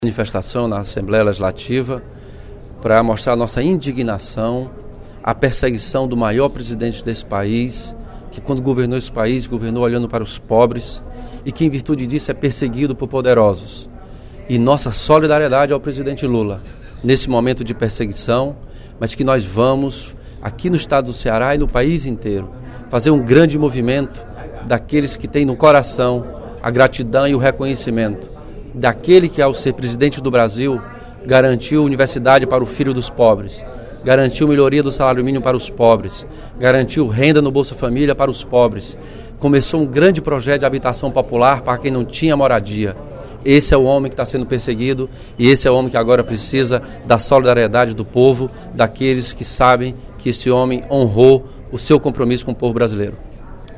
O deputado Elmano Freitas (PT) manifestou, no primeiro expediente da sessão plenária desta sexta-feira (11/03), o seu repúdio à decisão do Ministério Público de São Paulo de pedir a prisão preventiva do ex-presidente Lula por lavagem de dinheiro e falsidade ideológica.